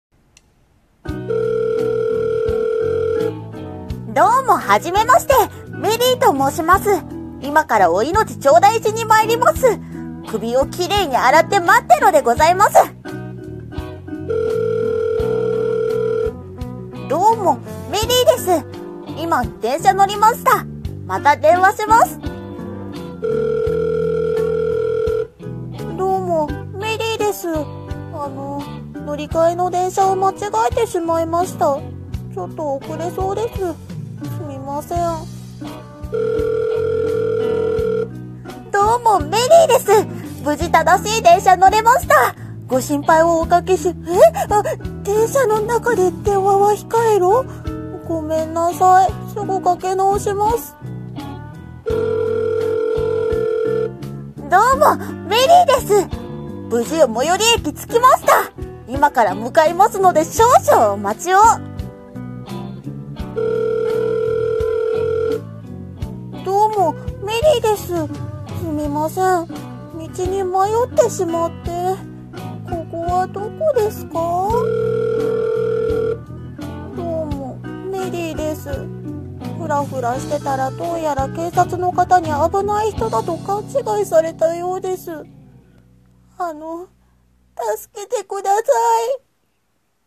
【ギャグ声劇台本】どうも、メリーです。